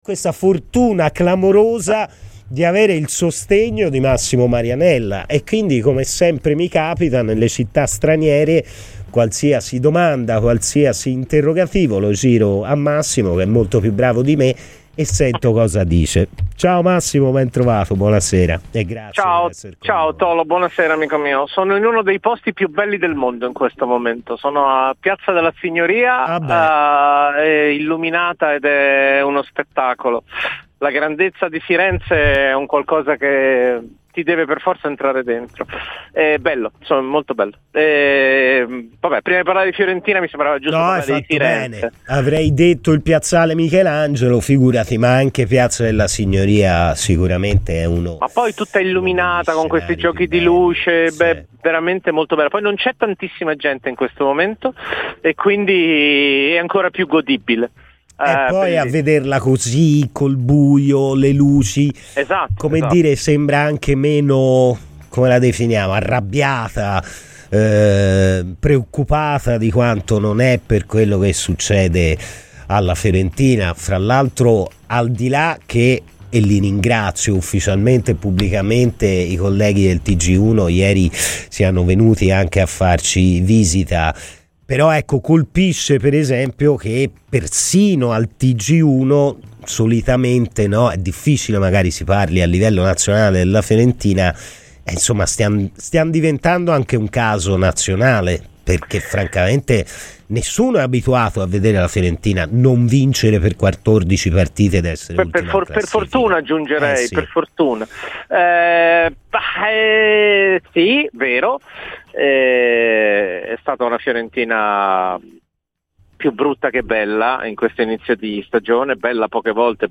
Intervenuto a Radio Firenzeviola, il giornalista e telecronista di Sky Sport Massimo Marianella ha commentato il momento della Fiorentina:.